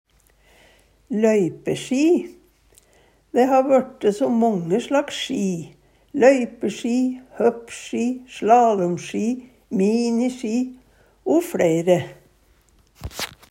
løypesji - Numedalsmål (en-US)